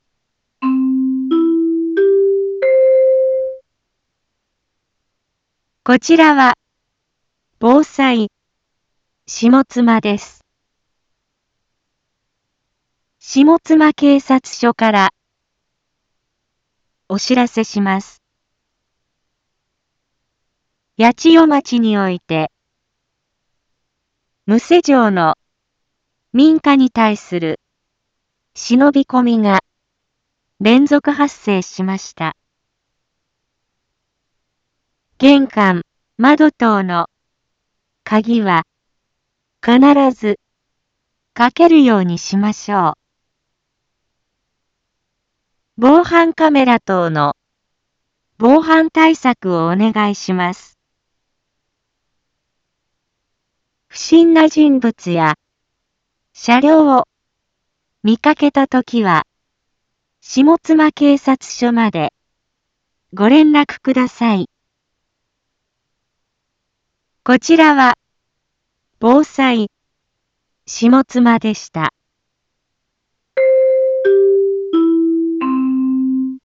一般放送情報
Back Home 一般放送情報 音声放送 再生 一般放送情報 登録日時：2021-09-10 12:31:20 タイトル：窃盗（忍び込み）への警戒について インフォメーション：こちらは防災下妻です。